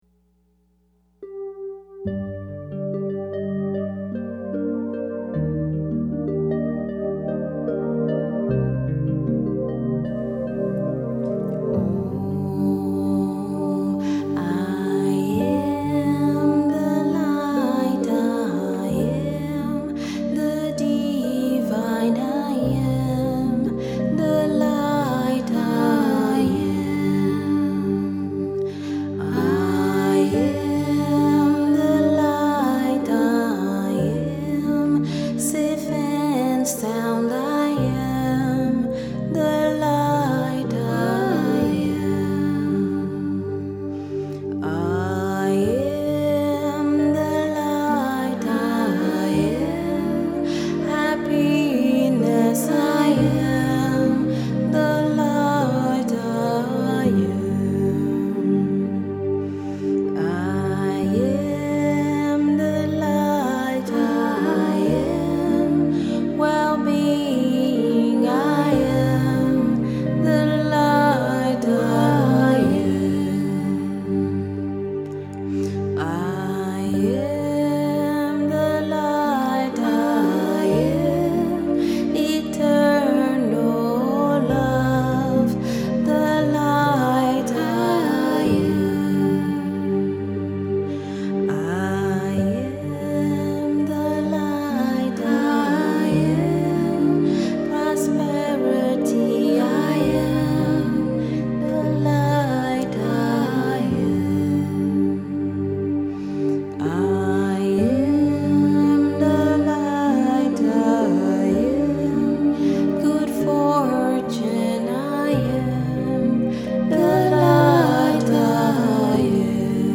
Kirtan & Devotional Music